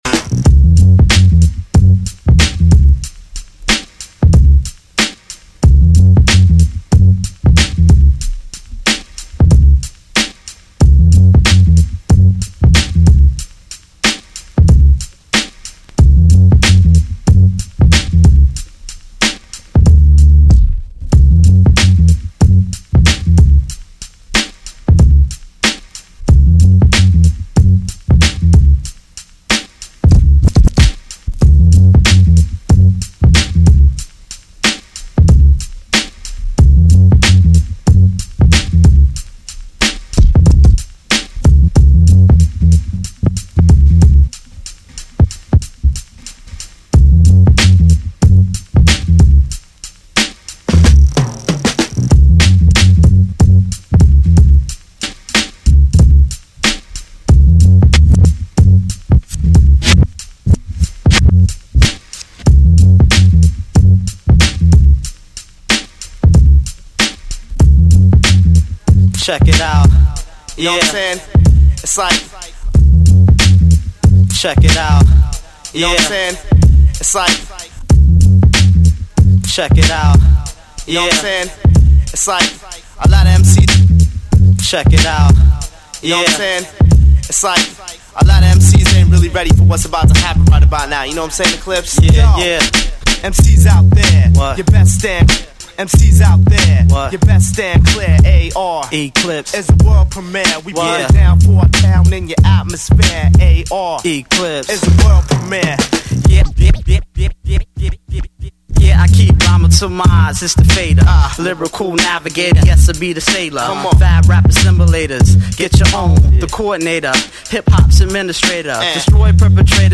And they’re on some real hip hop ish to boot, respect!
beat juggling